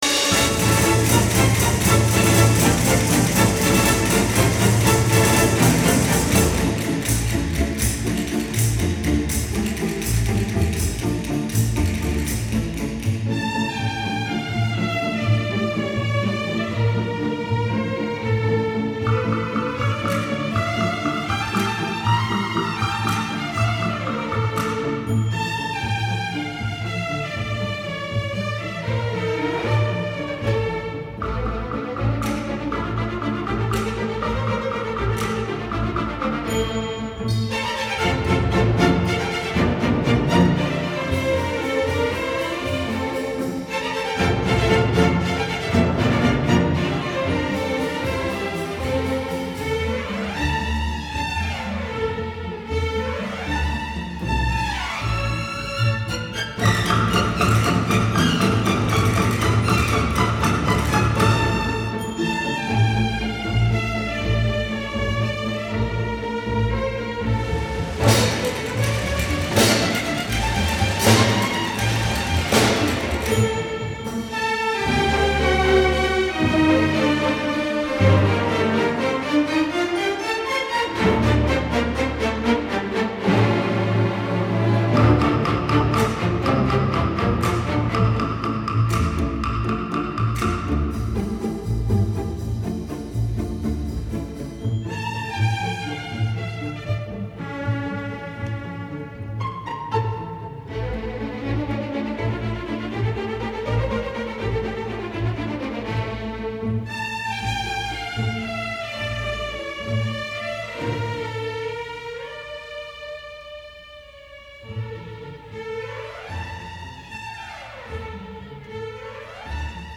古典音乐最通俗动听的曲目 空间感与动态猛劲的最佳典范.